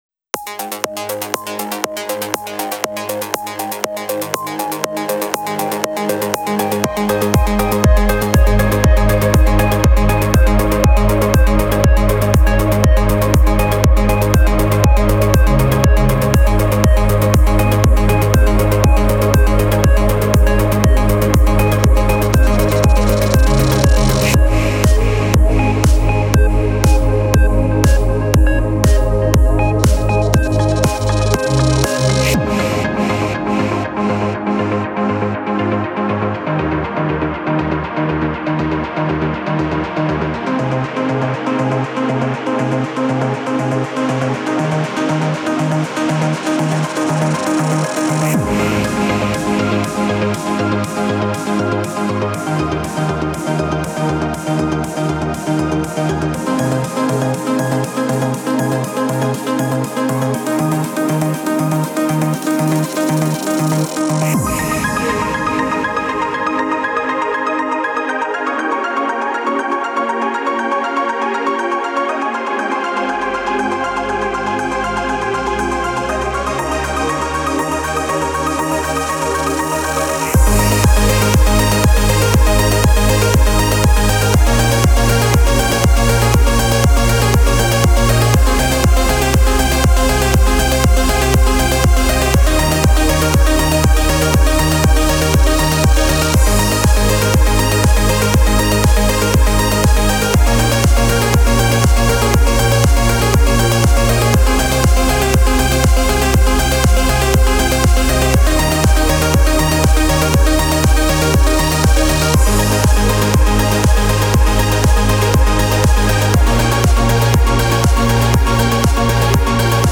BEST ELECTRO G-Q (39)